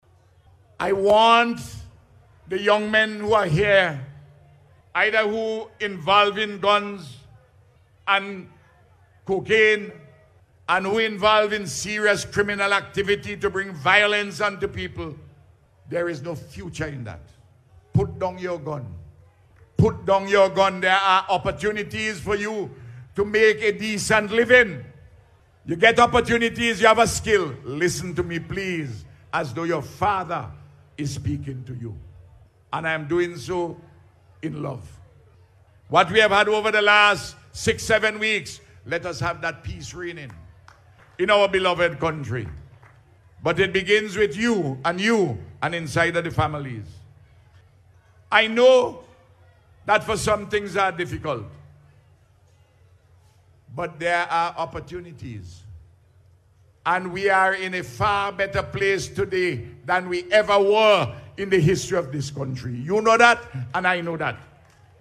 Dr. Gonsalves, who is also the Minister of National Security, spoke on the issue during his address at the Unity Labour Party’s Celebration Rally on Sunday night.